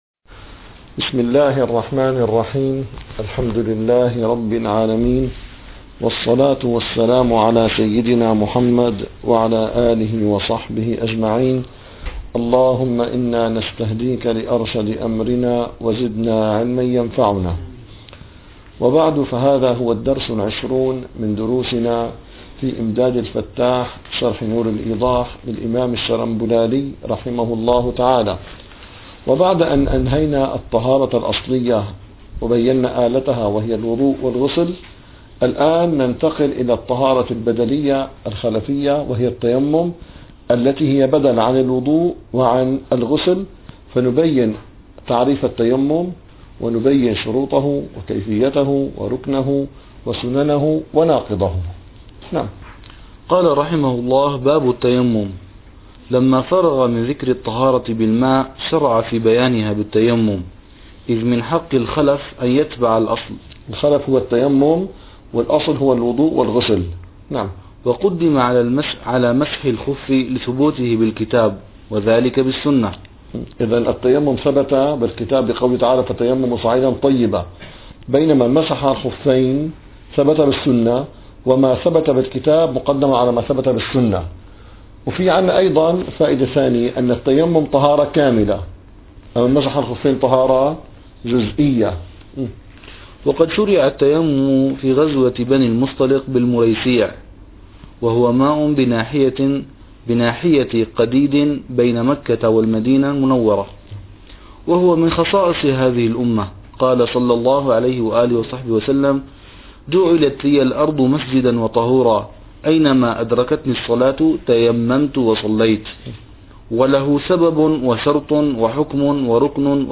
- الدروس العلمية - الفقه الحنفي - إمداد الفتاح شرح نور الإيضاح - 20- باب التيمم